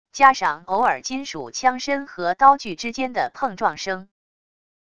加上偶尔金属枪身和刀具之间的碰撞声wav音频